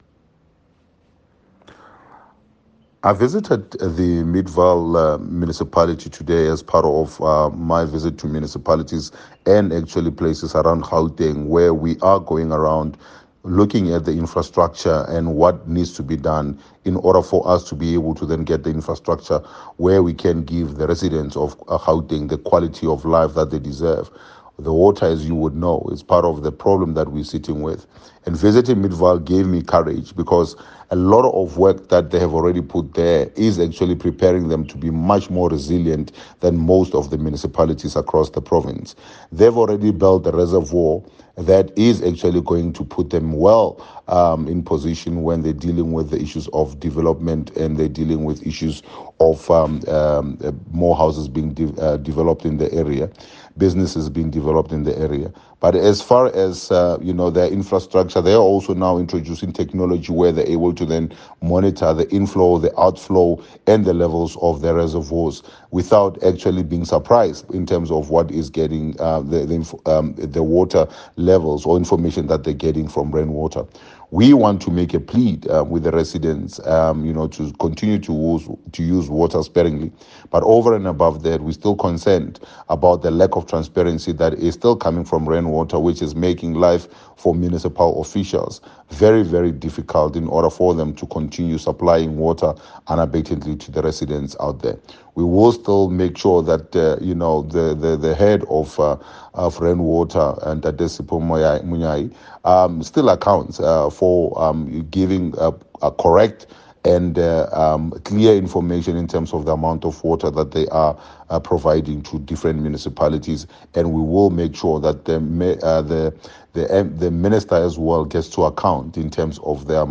Note to Editors: Please find photos of the visit here, here and here, and a soundbite by Solly Msimanga MPL